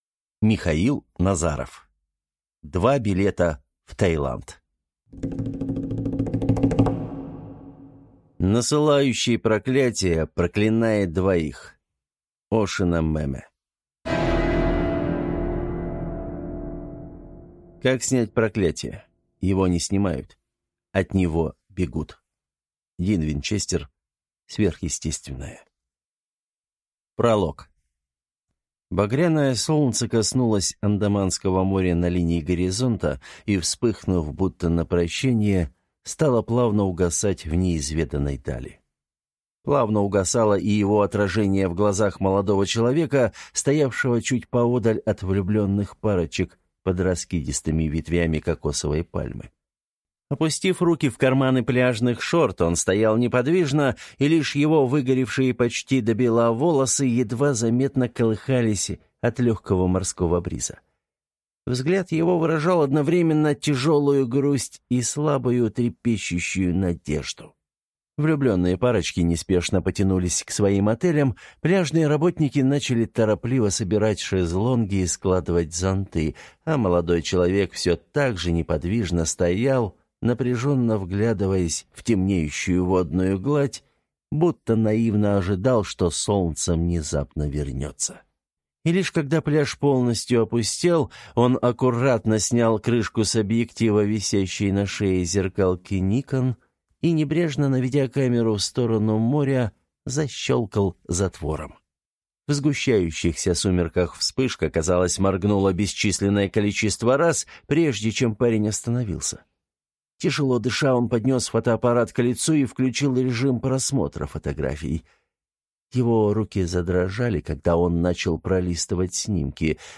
Аудиокнига Два билета в Таиланд | Библиотека аудиокниг